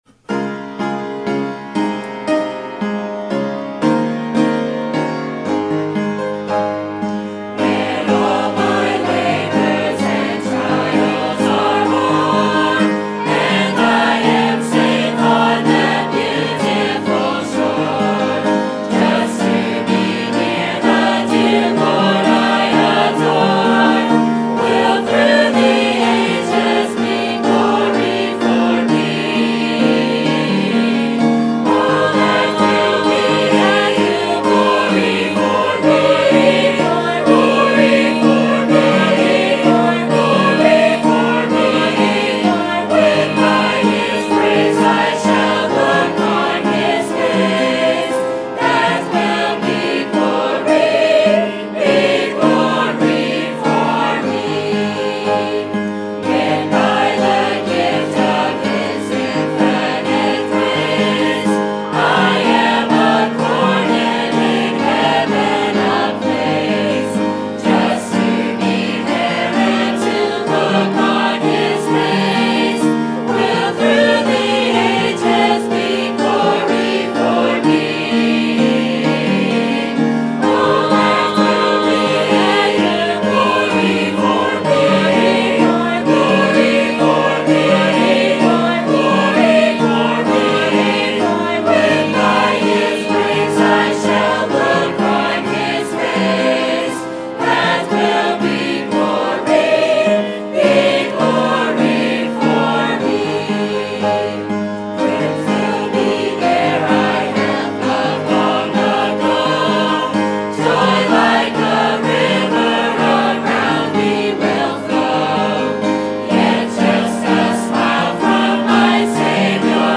Sermon Topic: Spring Revival 2015 Sermon Type: Special Sermon Audio: Sermon download: Download (19.86 MB) Sermon Tags: Revelation Revival Knocking Pursuing